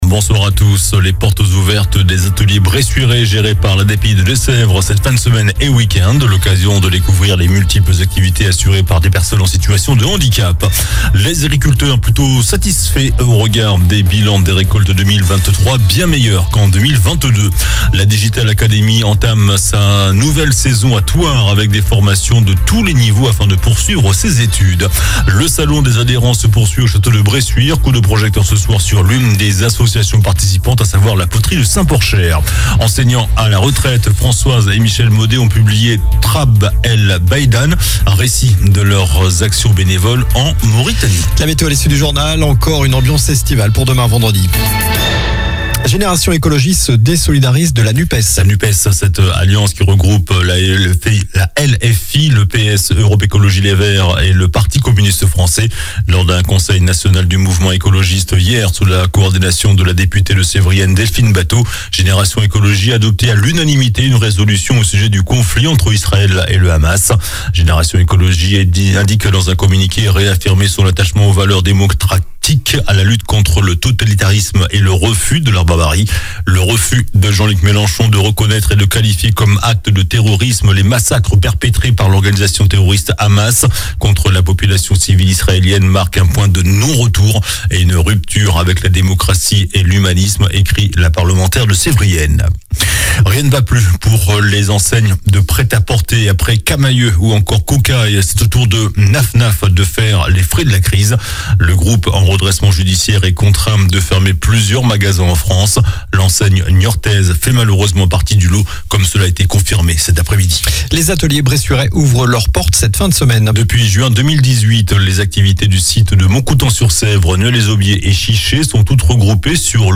JOURNAL DU JEUDI 12 OCTOBRE ( SOIR )